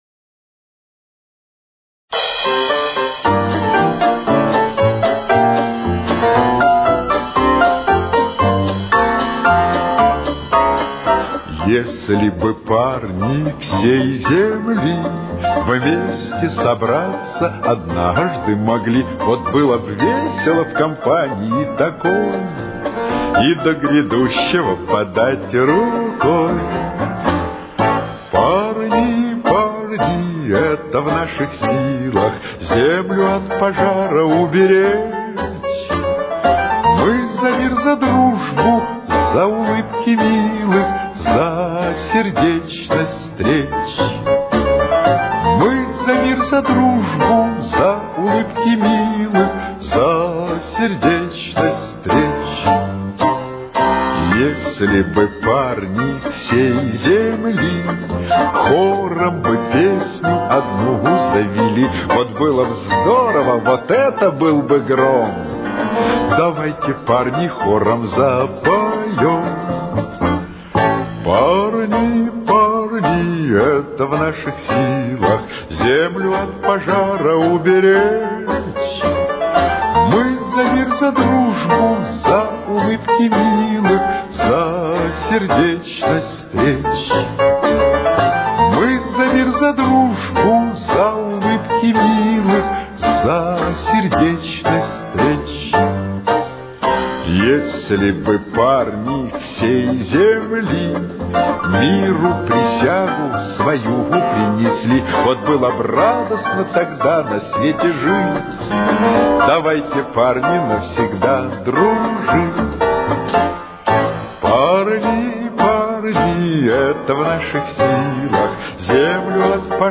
Фа мажор. Темп: 115.